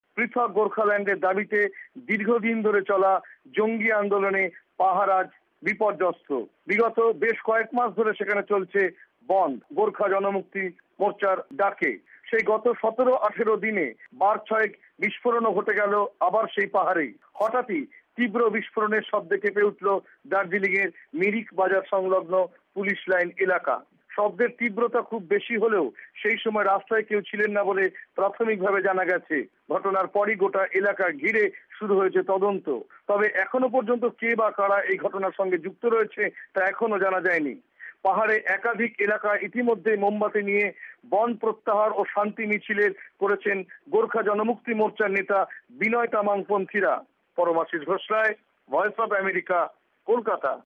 রিপোর্ট দার্জিলিং